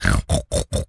pig_sniff_05.wav